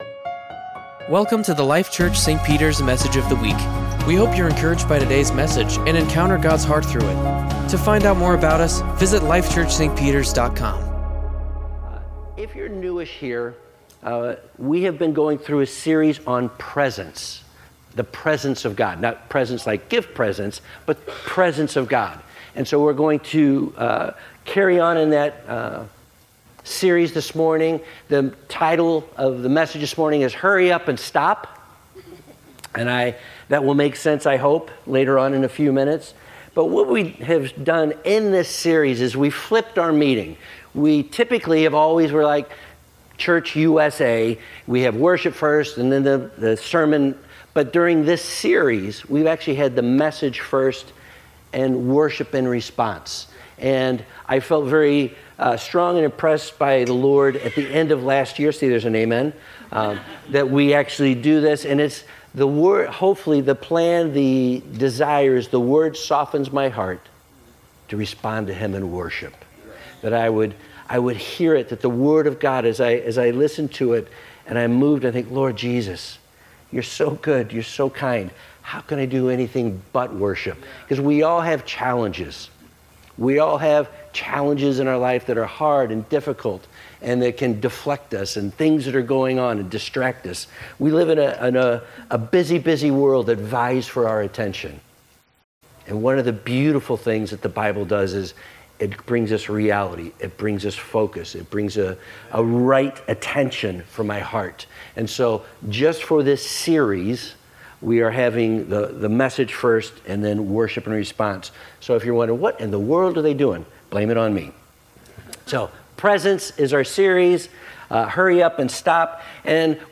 Sermons | Life Church - St. Peters